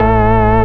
orgTTE54010organ-A.wav